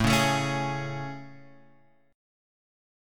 AM7 chord {5 4 7 6 x 4} chord